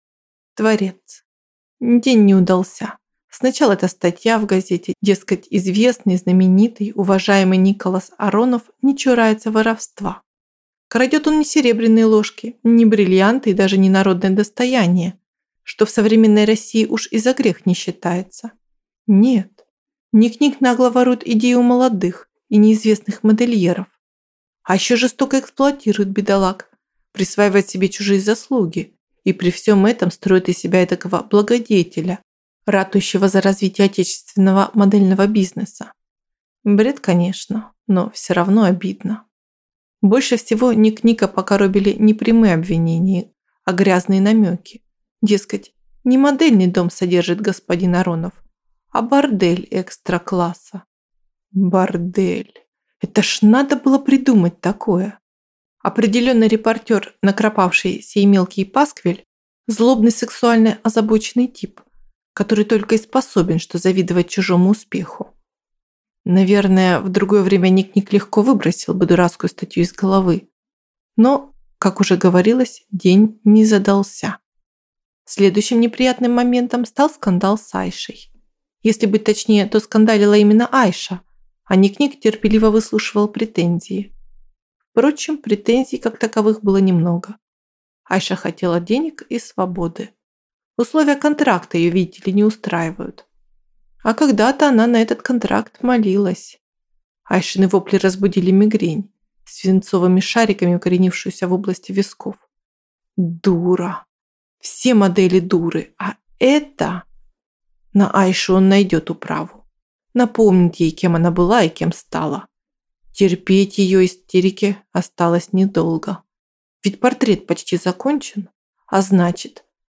Аудиокнига Философия красоты | Библиотека аудиокниг